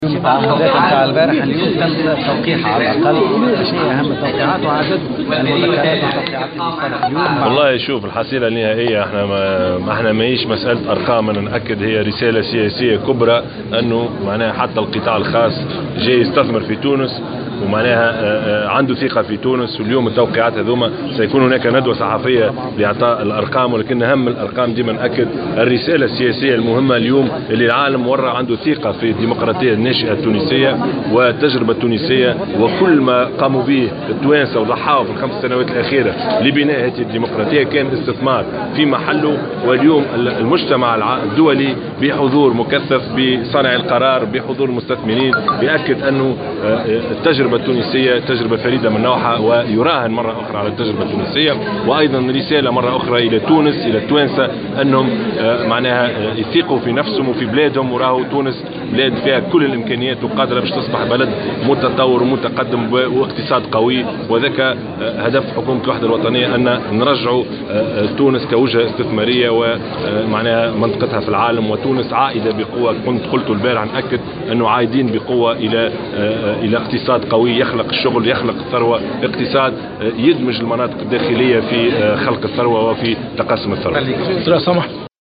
أكد رئيس الحكومة في تصريح للجوهرة "اف ام" اليوم الأربعاء 30 نوفمبر2016 أن عقد مؤتمر الإستثمار في تونس يؤكد أن التجربة التونسية فريدة من نوعها داعيا التونسيين إلى استعادة ثقتهم في بلدهم.